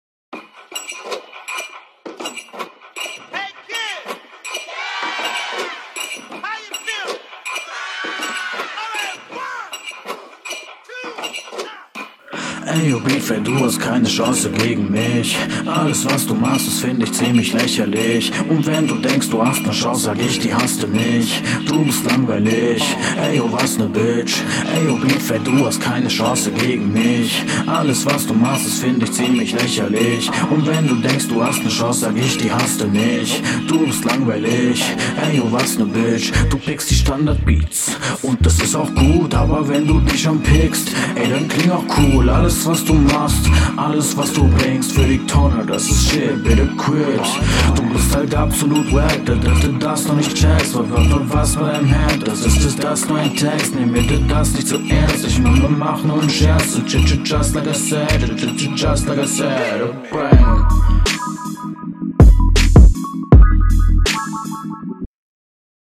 Puh, da hast du dir mal wieder einen fiesen Beat gepickt.